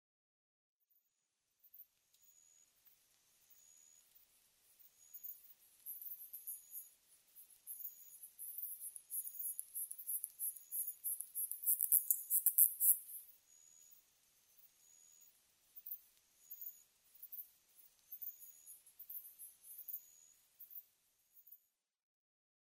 ホソクビツユムシ　Shirakisotima japonicaキリギリス科
日光市稲荷川中流　alt=730m  HiFi --------------
Rec.: MARANTZ PMD670
Mic.: audio-technica AT825